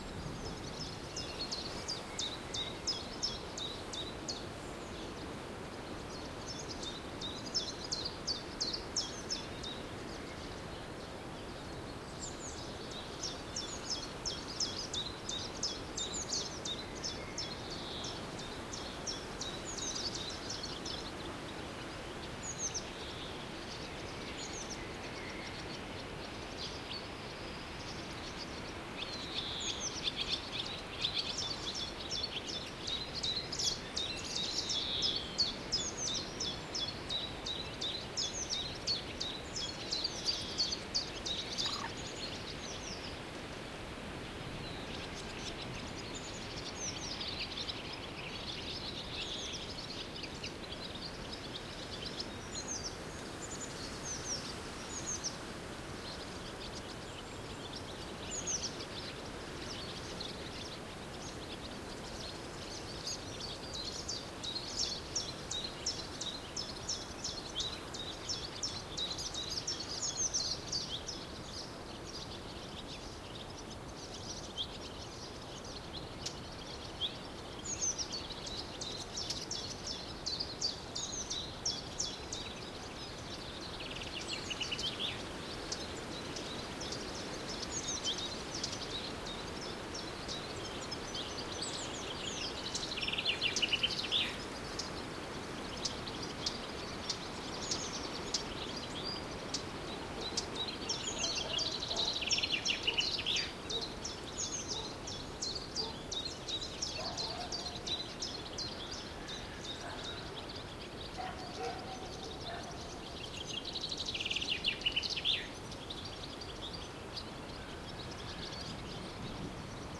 outdoorNatureLoop.ogg